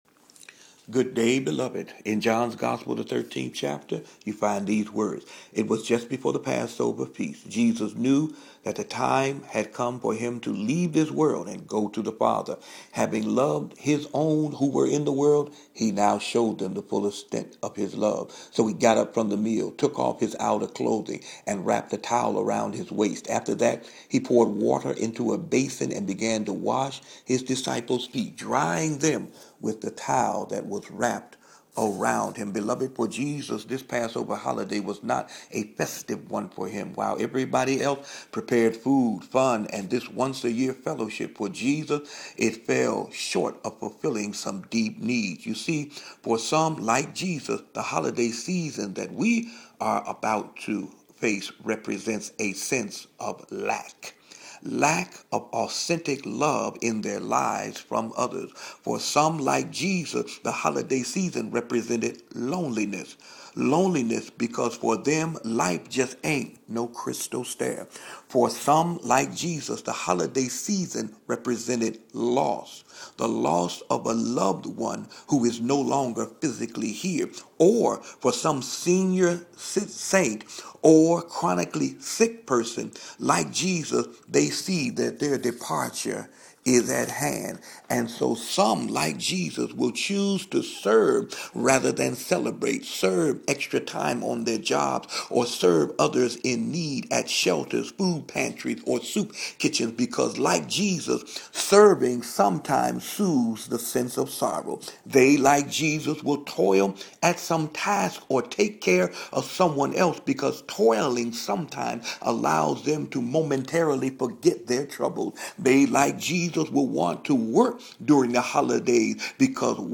Hump Day Homily